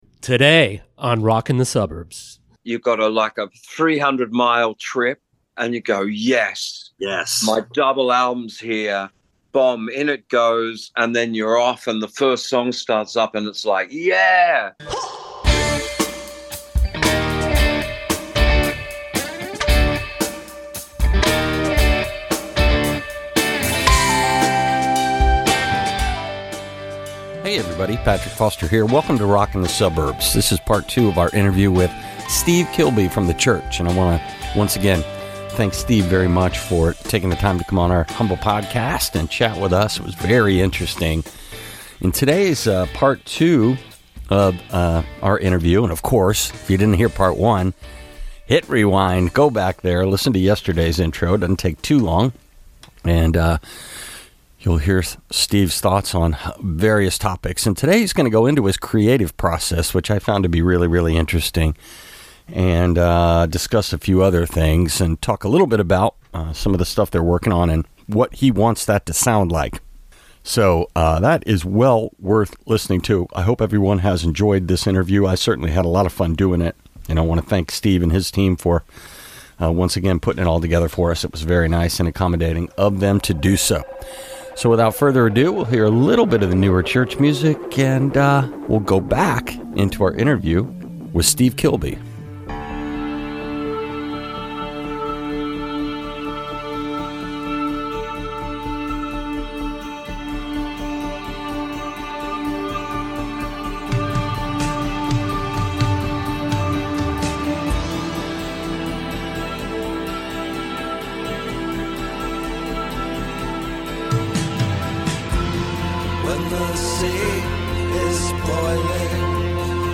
Interview: Steve Kilbey of the Church, Part 2